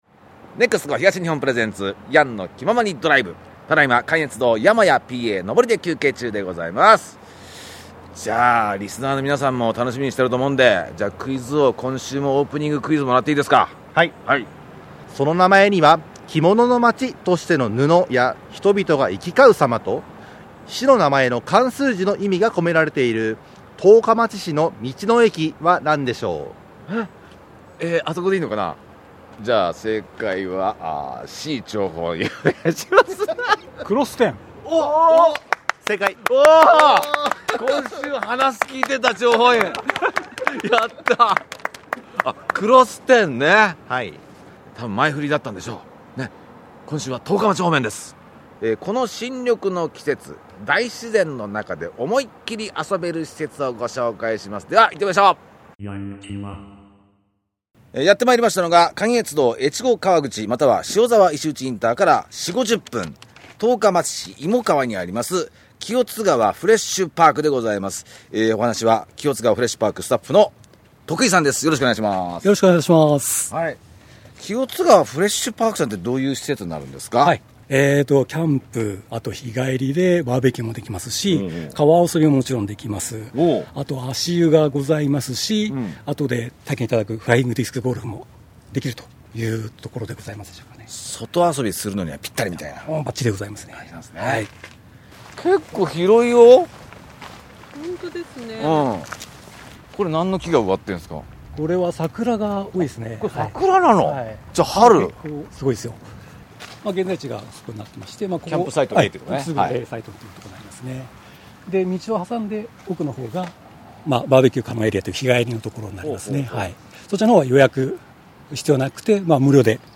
やってまいりましたのは、関越道・越後川口または塩沢石打ＩＣから約５０分「清津川フレッシュパーク」です。